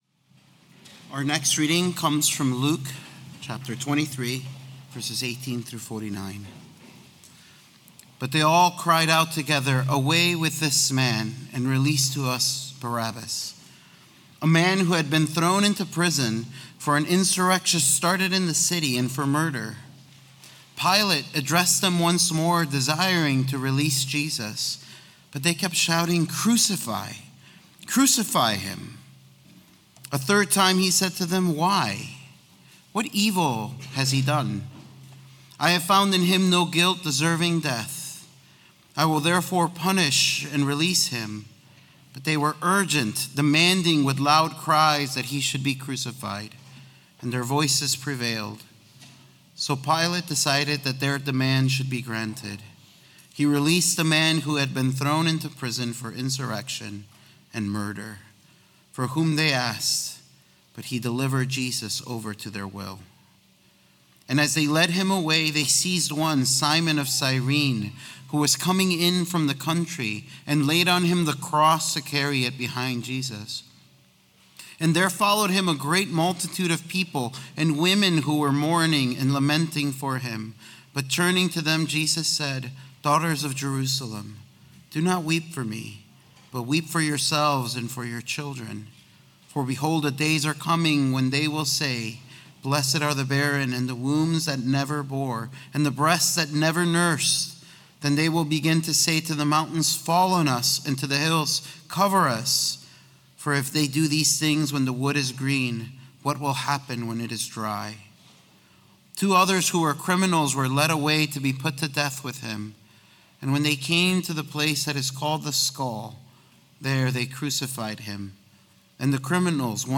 New Testament Sermons